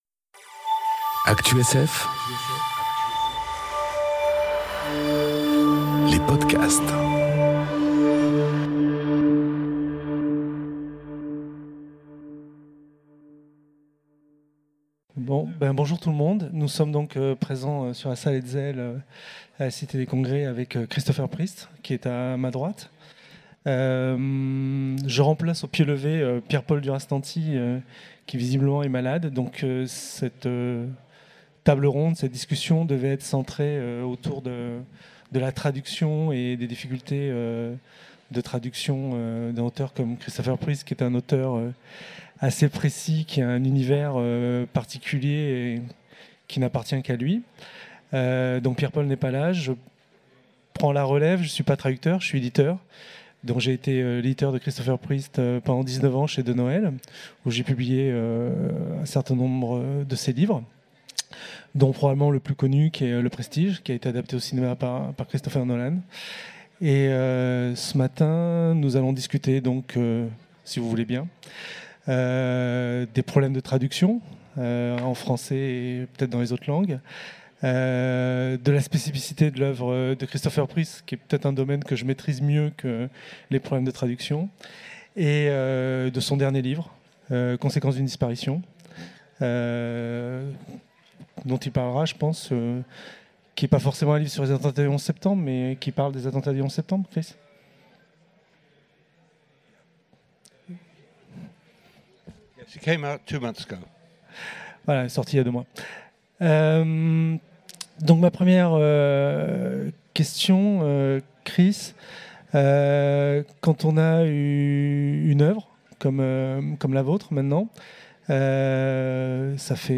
Conférence
Rencontre avec un auteur